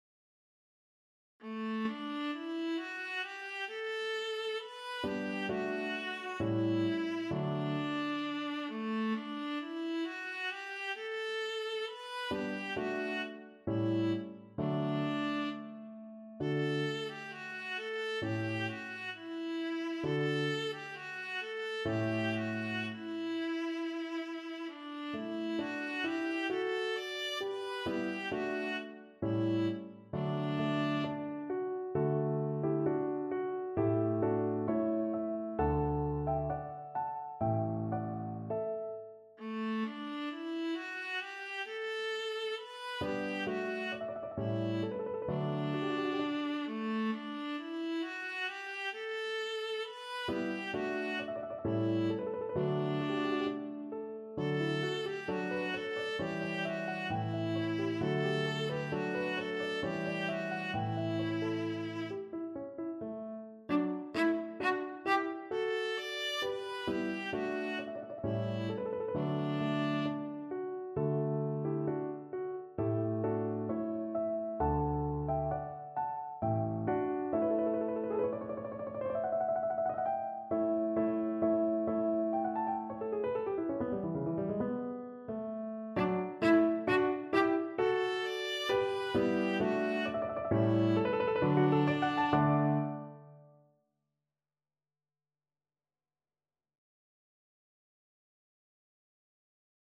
Viola version